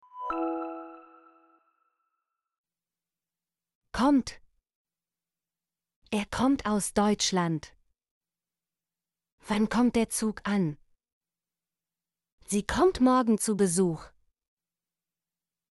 kommt - Example Sentences & Pronunciation, German Frequency List